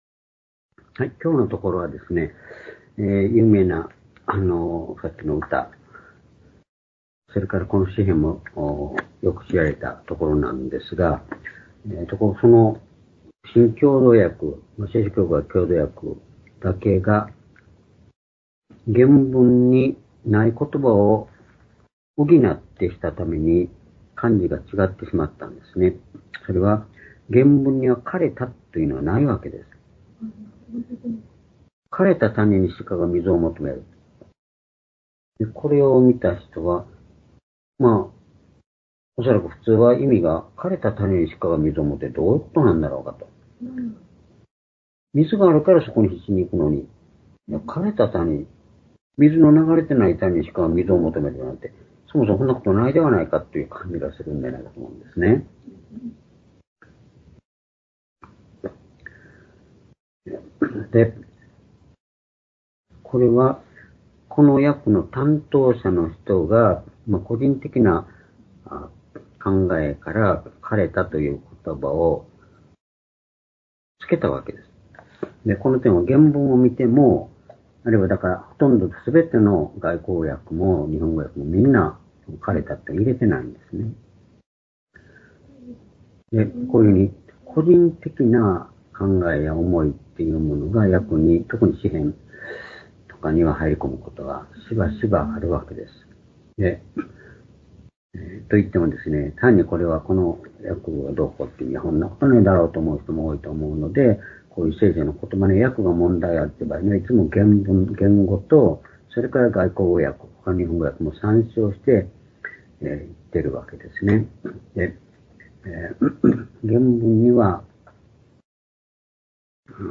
（主日・夕拝）礼拝日時 2024年5月21日(夕拝) 聖書講話箇所 「悲しみのなかから神のみを求める」 詩編42編2～4節 ※視聴できない場合は をクリックしてください。